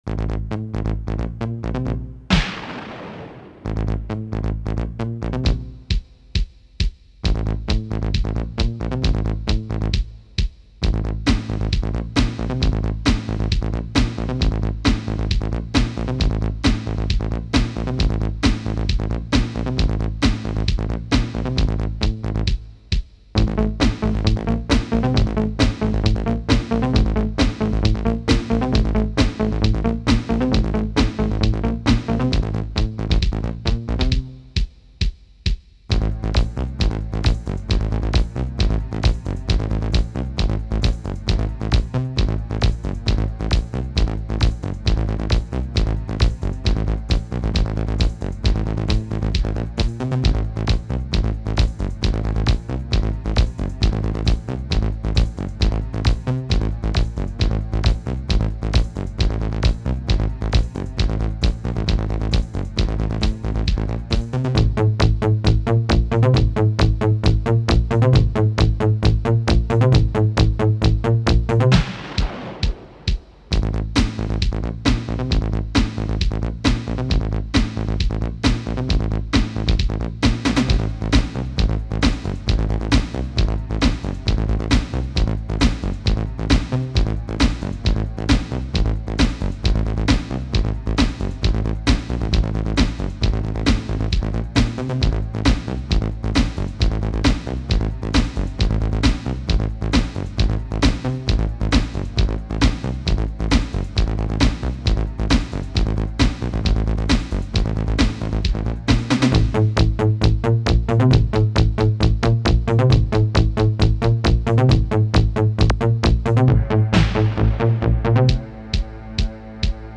Synthar som anv�nds �r: Roland MKS-50, Korg DW-6000, Nord Lead 2, Yamaha TX16W och en Boss DR-660... som mixas genom ett StudioMaster direkt in i ett Ensoniq PCI ljudkort i datorn. L�ten b�rjar med en NL2 som bas och ett MKS-50 blipp. Alla trummor (hihats, tamb, open hihat och metal snare) kommer fr�n en Boss DR-660 och baskaggen �r fr�n TX16W-samplern. Extra basen (0:03 + 0:18) kommer fr�n DW-6000 (sawbass).
Svep-ljuden kommer fr�n NL2 och extra basen fr�n DW-6000 (0:29).
Senare byter MKS-50 till en mera resonans-blipp-bas (1:05) och DW-6000 (~sawbass).